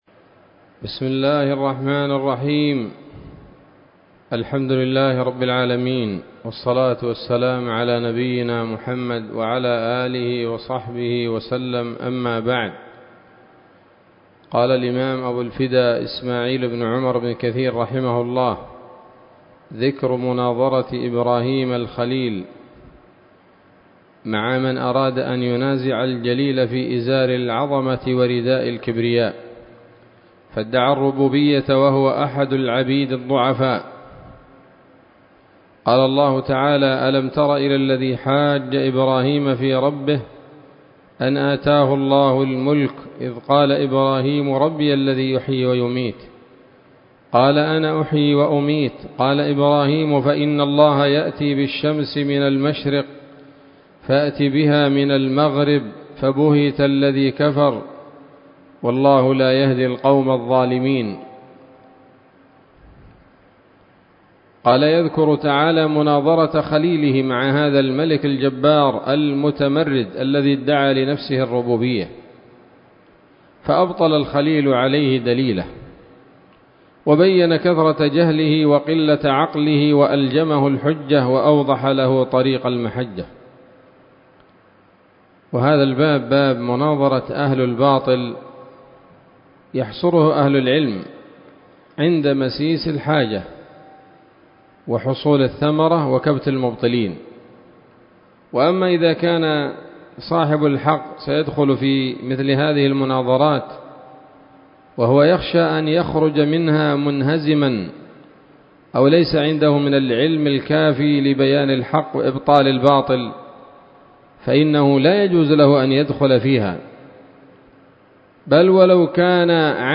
الدرس الرابع والأربعون من قصص الأنبياء لابن كثير رحمه الله تعالى